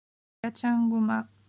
Ecoutez comment on dit certains mots de l’histoire en japonais: